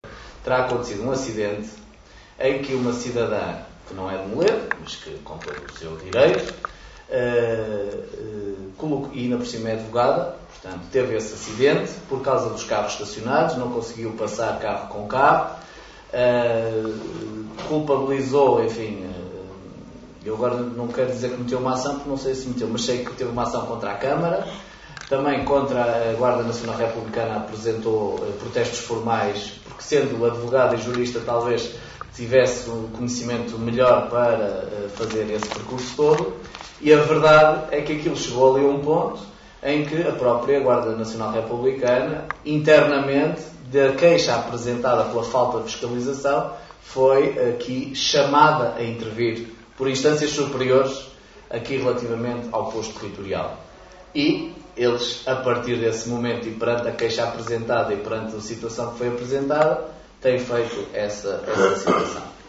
Miguel Alves, presidente da Câmara de Caminha justificou a atuação “mais musculada” da GNR, com um acidente ocorrido no Verão passado, acidente esse em que esteve envolvida uma advogada que apresentou várias queixas.